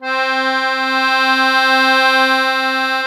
MUSETTE1.3SW.wav